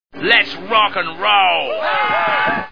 The Simpsons [Celebrities] Cartoon TV Show Sound Bites